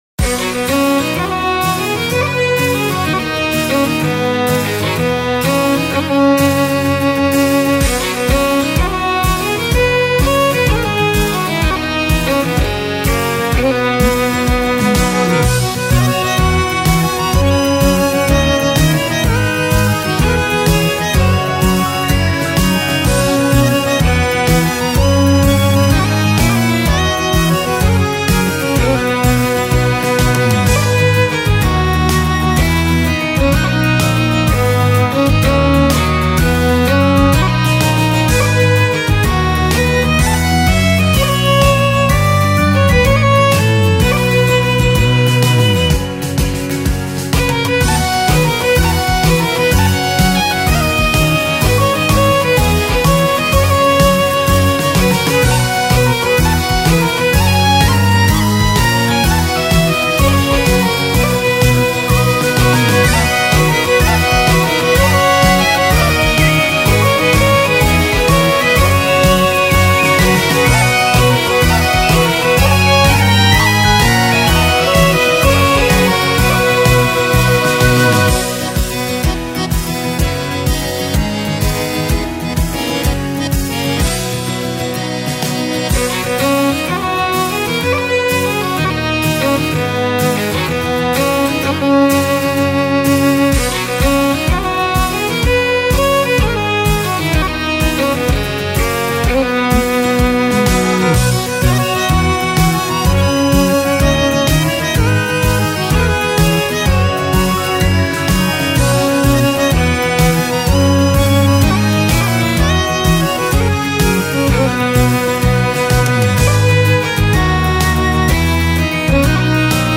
ジャンルケルト風音楽
BPM１２６
使用楽器フィドル、アコーディオン
解説明るく楽しいケルト風フリーBGMです。